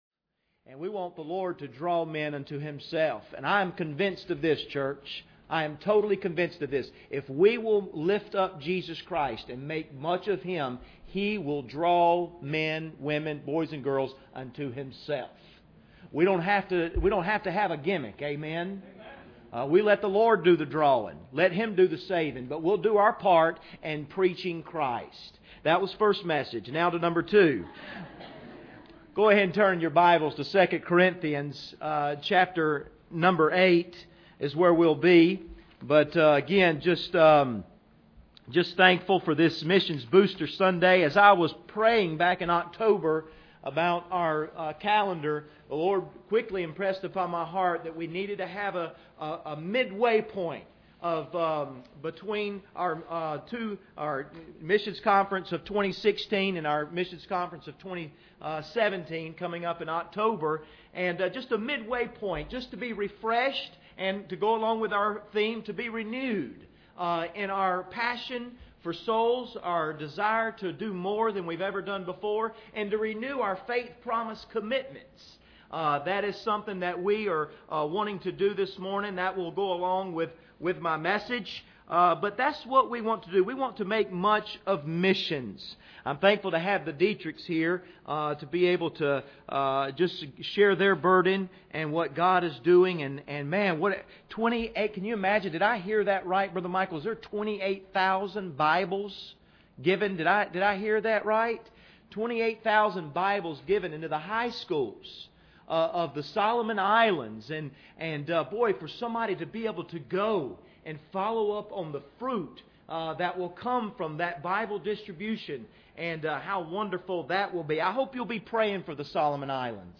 2 Corinthians 8:1-7 Service Type: Sunday Morning Bible Text